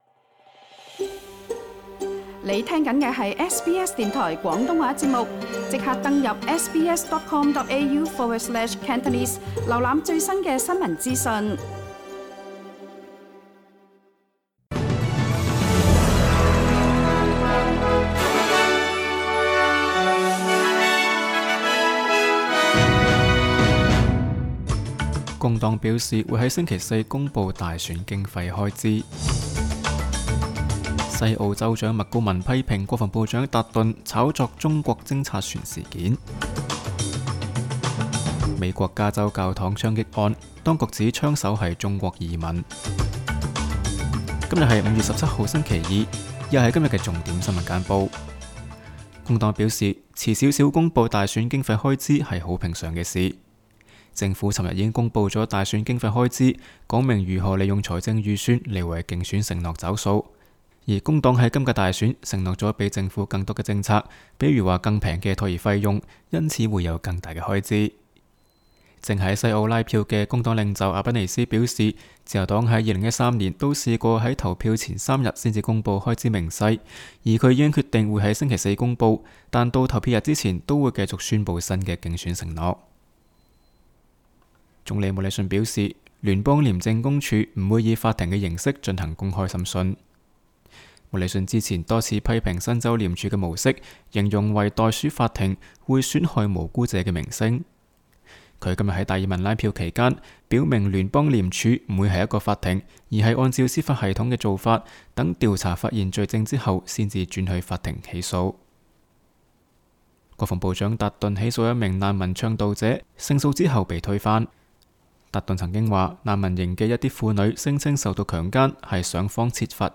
SBS 新闻简报（5月17日）